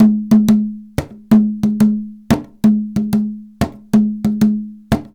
PERC 27.AI.wav